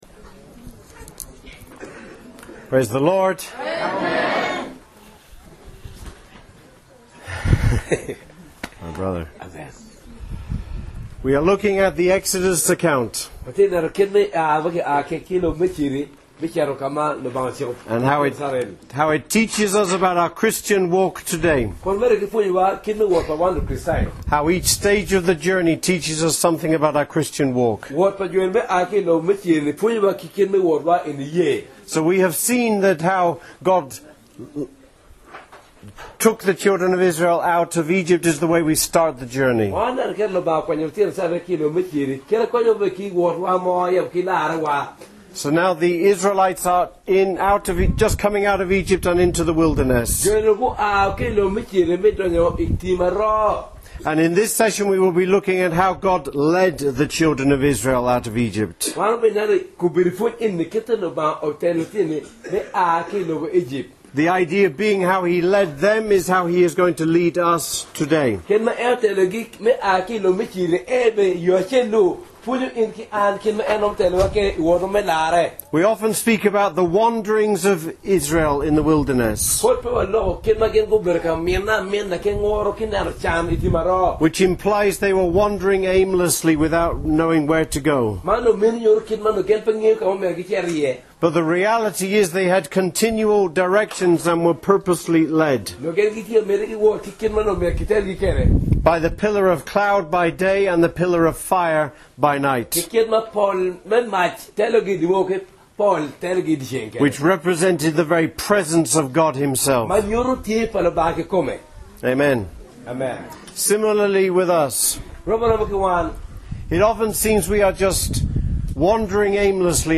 How God led the children of Israel in the wilderness is how He will guide us today. Recorded at Amuru, Northern Uganda with translation into the Luo language.